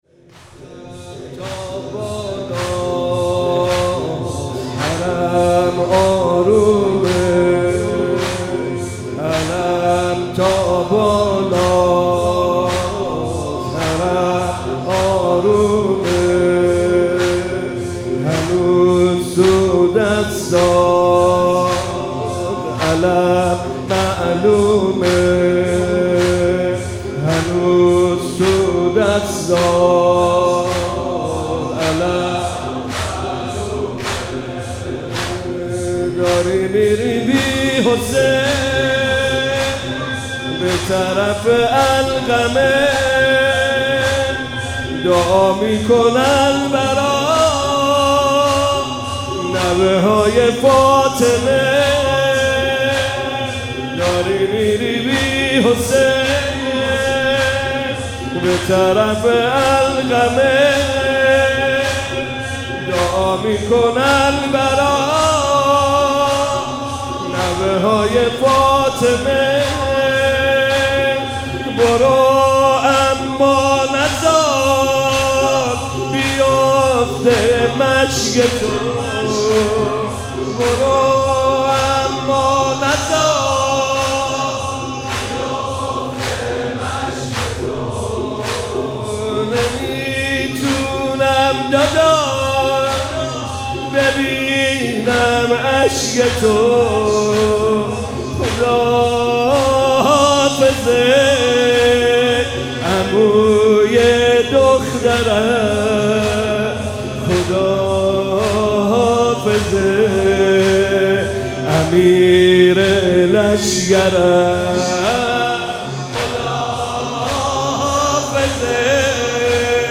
شب تاسوعا محرم 97 - زمینه - علم تا بالاست